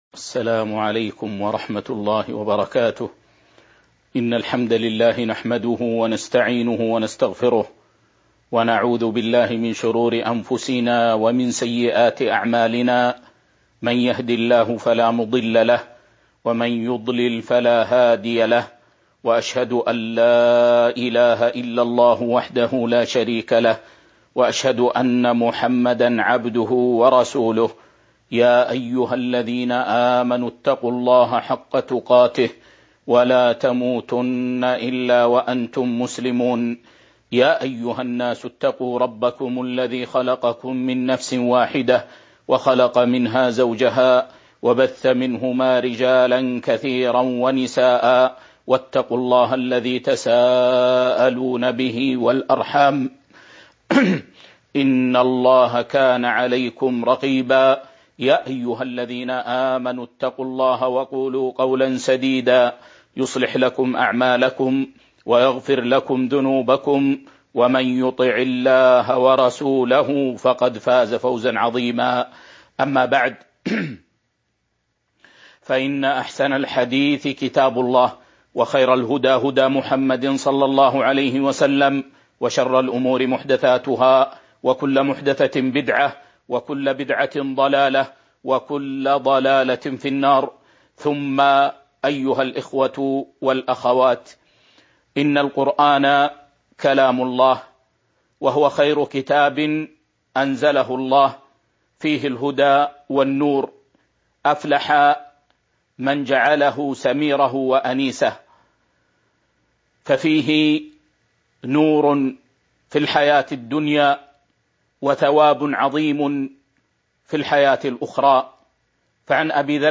تاريخ النشر ١٠ رمضان ١٤٤٢ هـ المكان: المسجد النبوي الشيخ